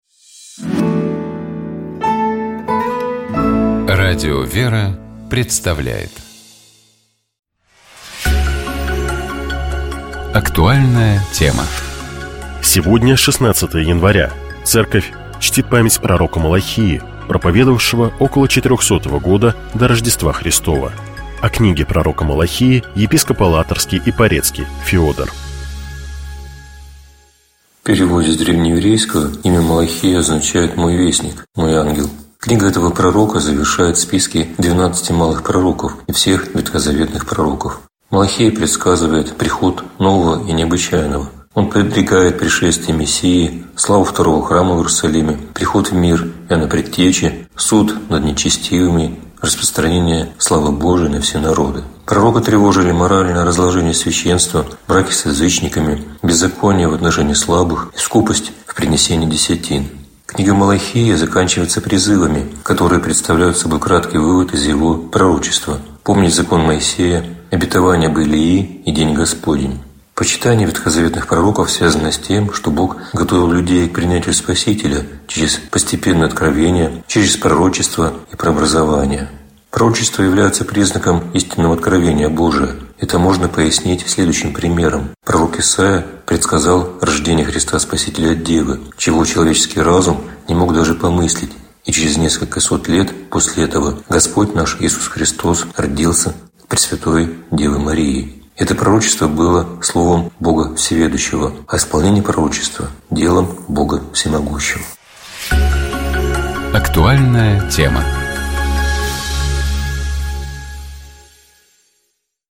О книге пророка Малахии, - епископ Алатырский и Порецкий Феодор.